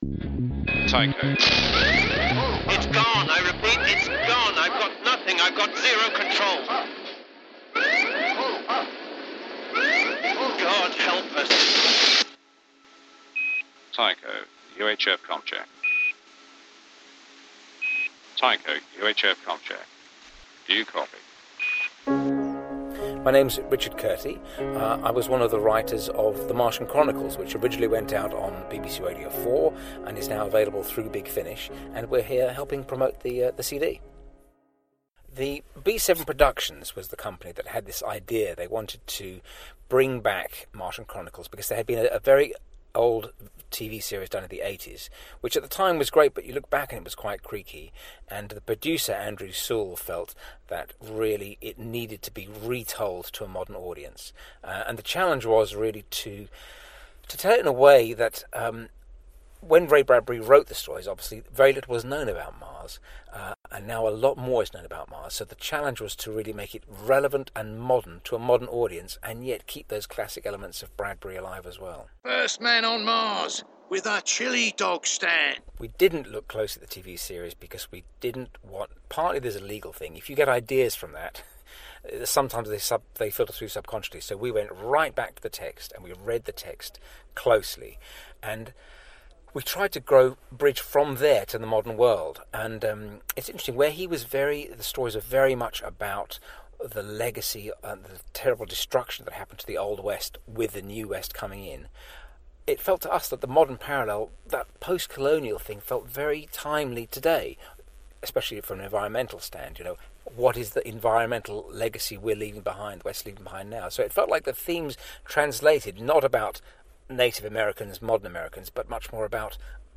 The Martian Chronicles- BBC - Interview